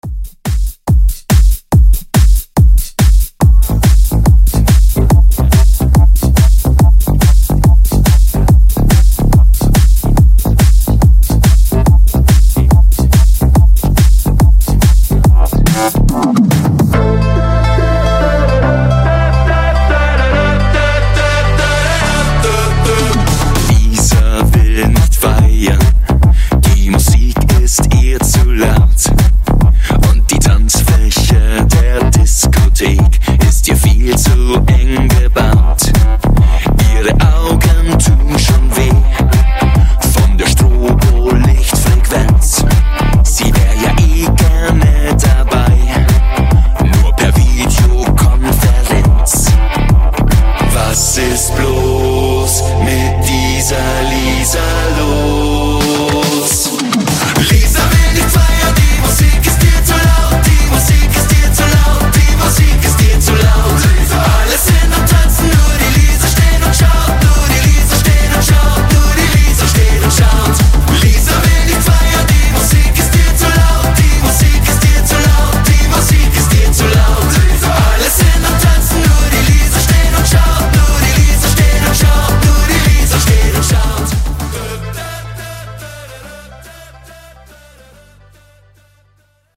Genre: DANCE
Clean BPM: 120 Time